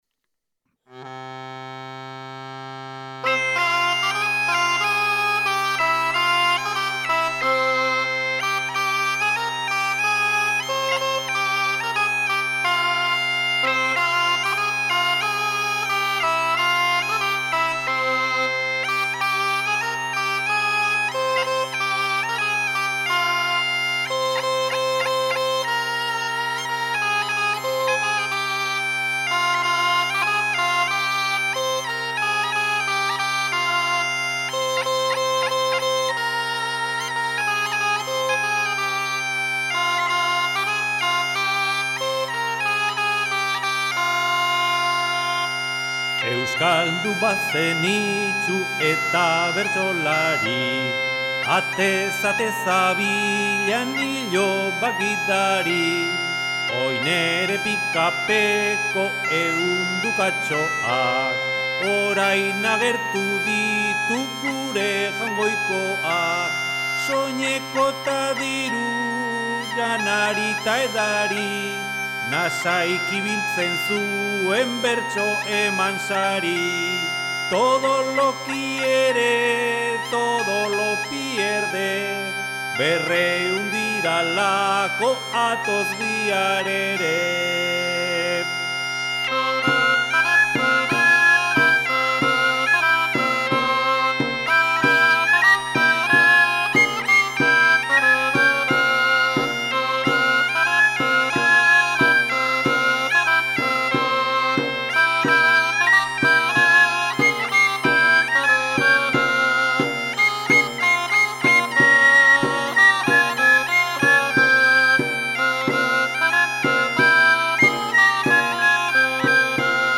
Oiartzungo Lezoti estudioan grabatuta.
Xirolarrua, txistu eta danbolina
Txirimia eta ahotsa